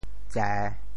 “债”字用潮州话怎么说？
债（債） 部首拼音 部首 亻 总笔划 10 部外笔划 8 普通话 zhài 潮州发音 潮州 zê3 文 中文解释 债 <名> (形声。